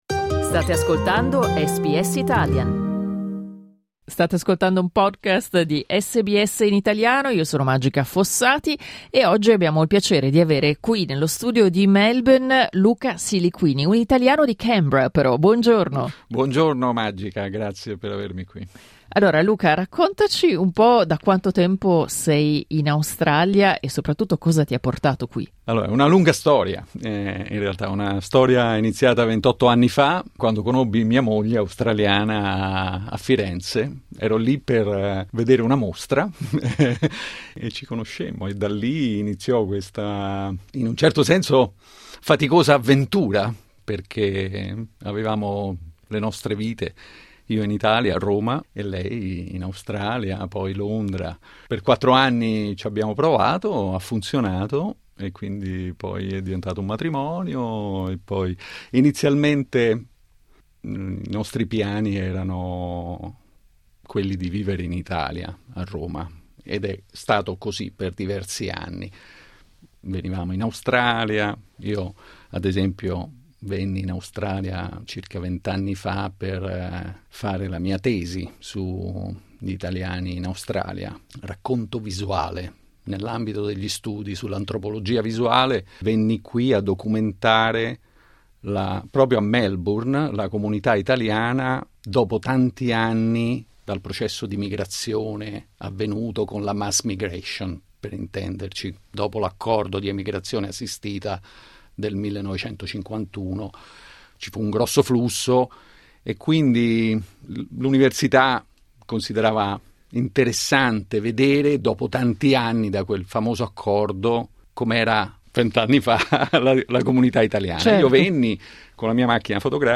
Clicca sul tasto "play" in alto per ascoltare l'intervista integrale I colori sono fondamentali nella mia vita e nella mia vita artistico-professionale.